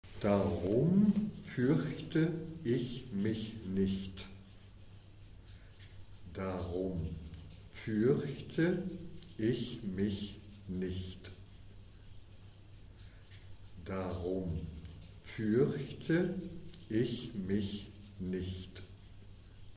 Prononciation
Darum furchte ich mich nicht - lent.mp3